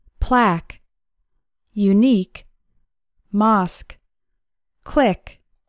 In words ending in QUE, it is pronounced like “K”: